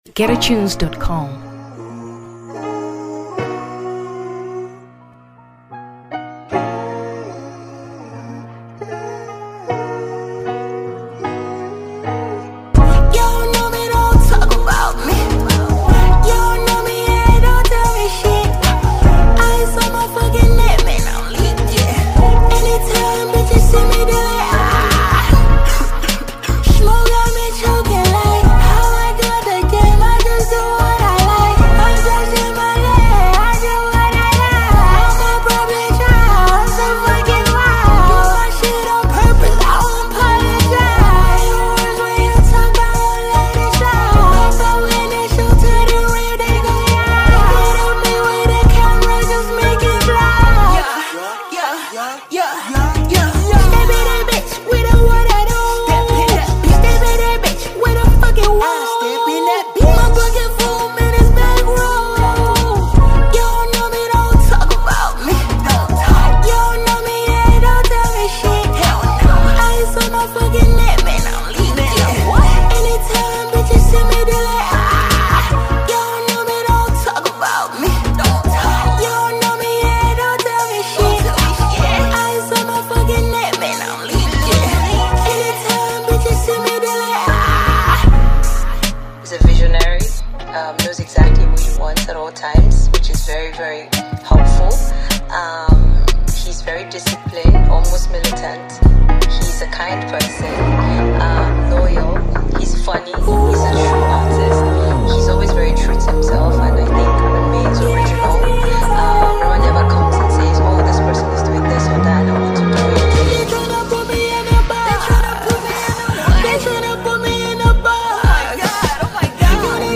Afrobeat 2023 Nigeria